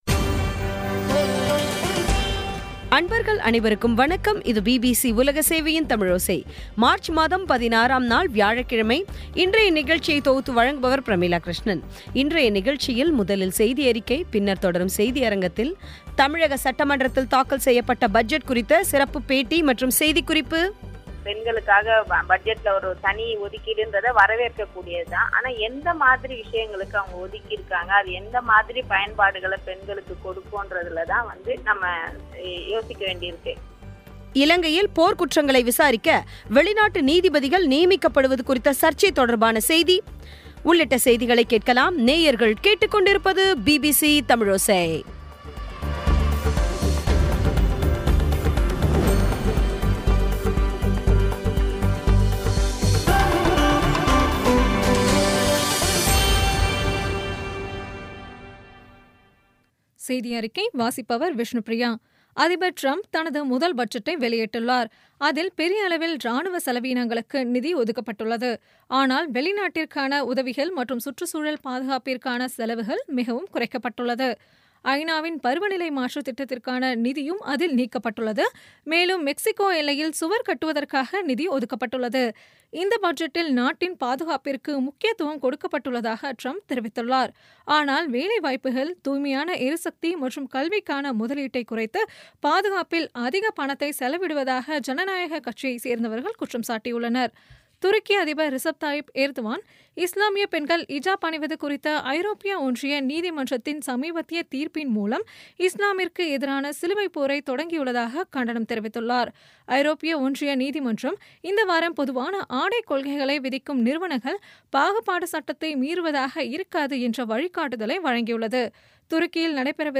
தமிழக சட்டமன்றத்தில் தாக்கல் செய்யப்ட்ட பட்ஜெட் குறித்த செய்தி மற்றும் பேட்டி இலங்கையில் போர் குற்றங்களை விசாரிக்க வெளிநாட்டு நீதிபதிகள் நியமிக்கப்படுவது குறித்த சர்ச்சை தொடர்பான செய்தி ஆகியவை கேட்கலாம்